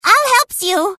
Tags: Everquest 2 Ratonga emote Heals me I dont think soes